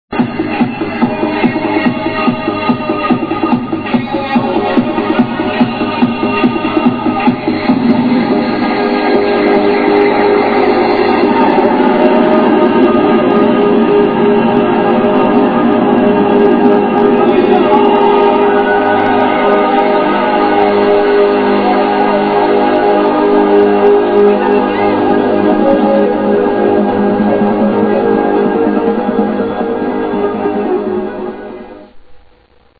the quality in the sample isn't that great